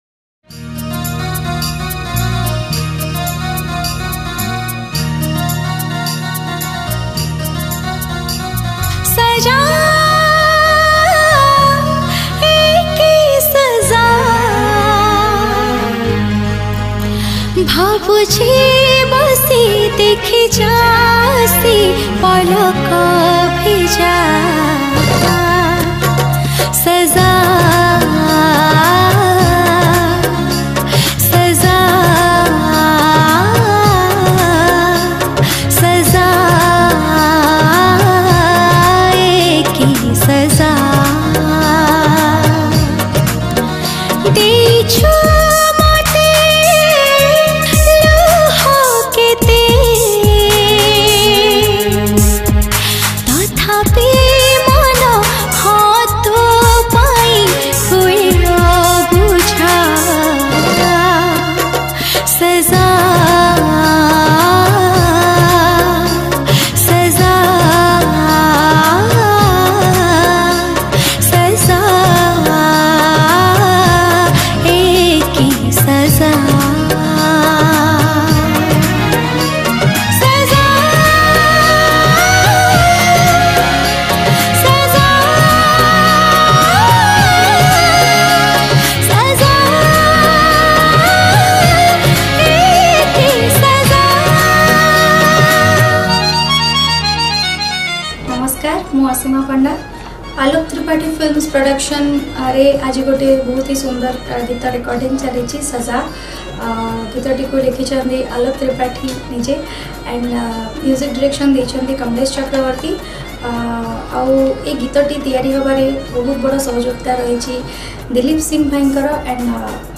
Odia Sad Song